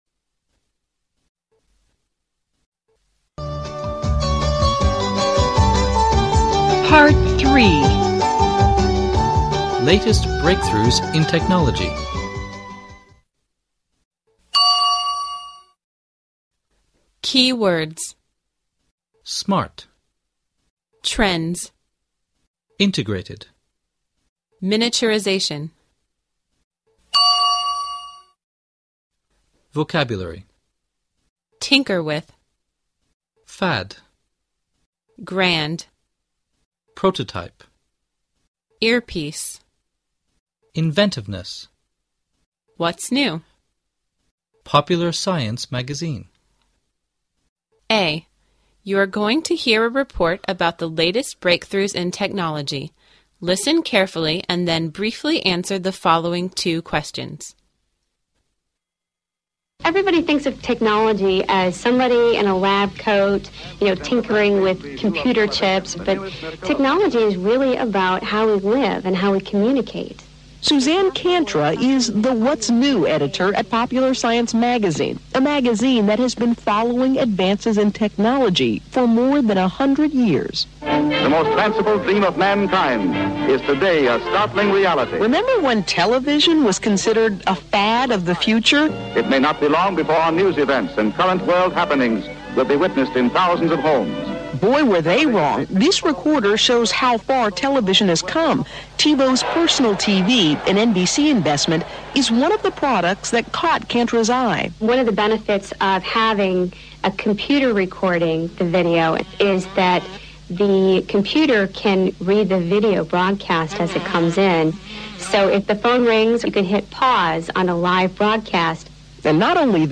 A. You're going to hear a report about the latest breakthroughs in technology, listen carefully and then briefly answer the following two questions.